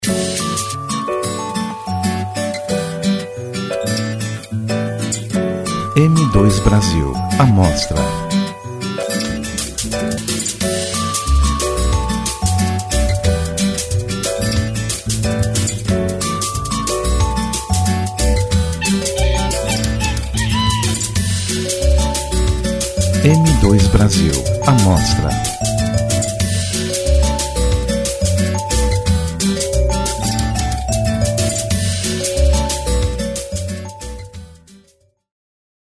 Músicas de Fundo para URA